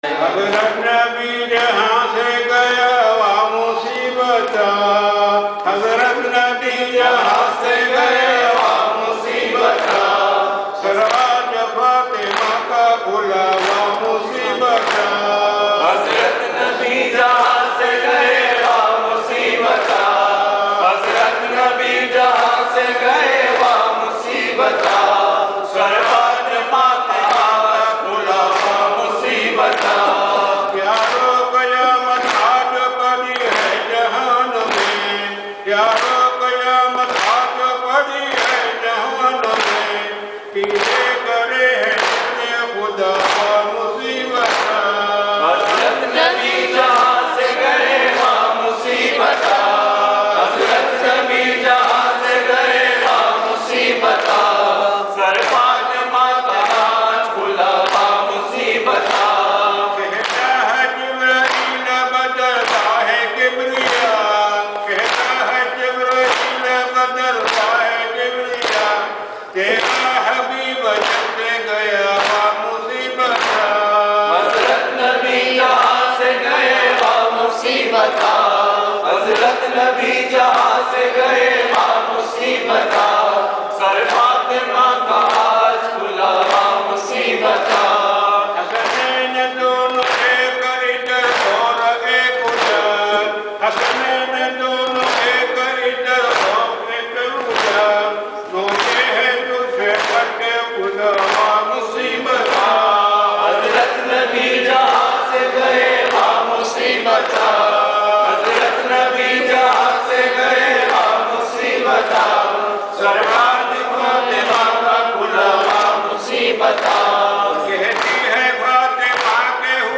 Submit lyrics, translations, corrections, or audio for this Nawha.